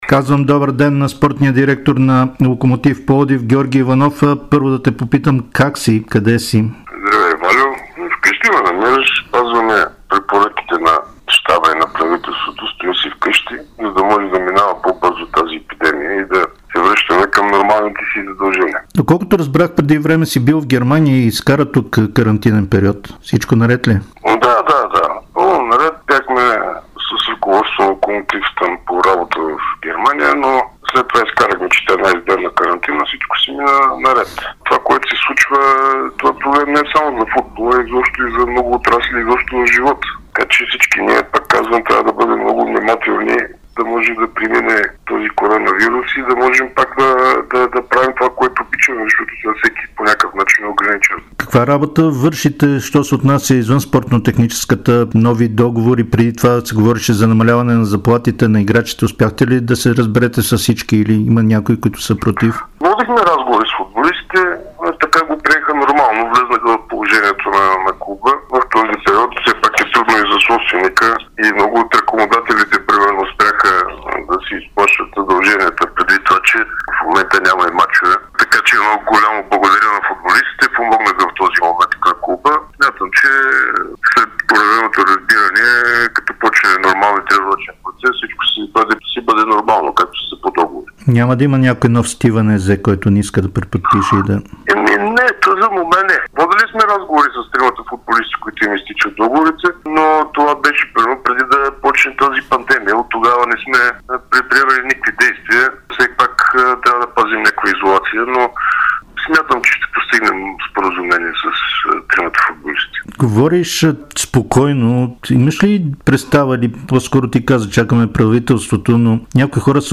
Спортният директор на Локомотив Пловдив Георги Иванов заяви пред dsport и Дарик радио, че отборът трябва да се възползва от проблемите в Левски, за да е пред него в класирането и да го отстрани на полуфиналите за Купата на България.